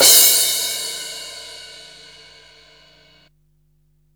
CRASH06   -L.wav